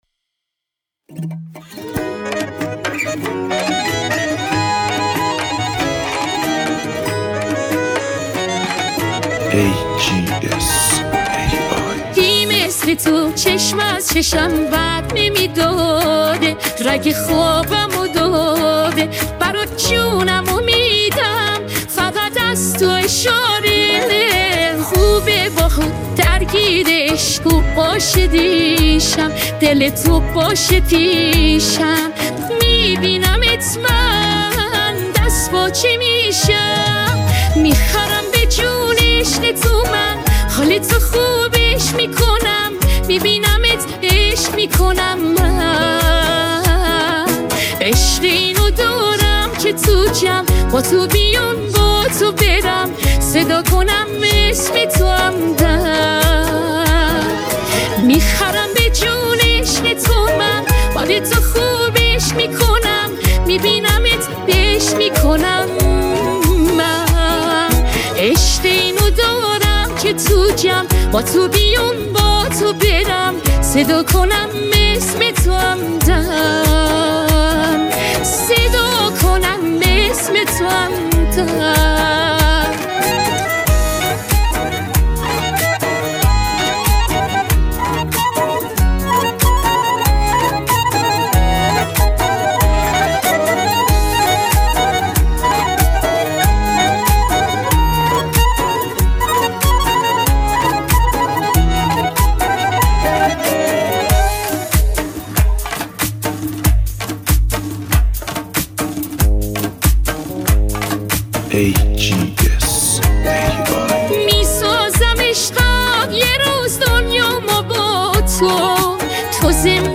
download new ai generated music